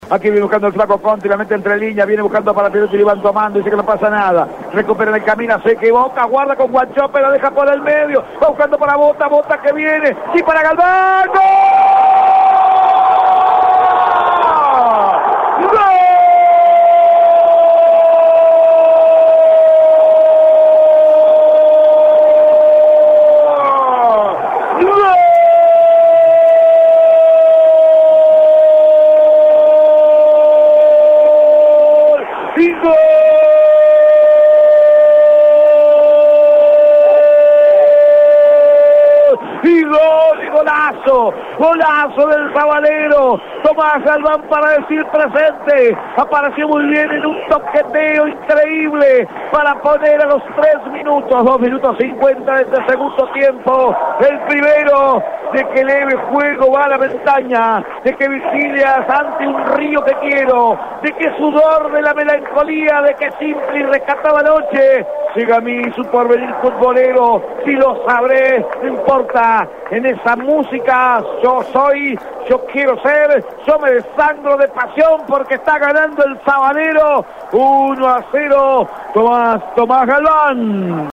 01-GOL-COLON-1.mp3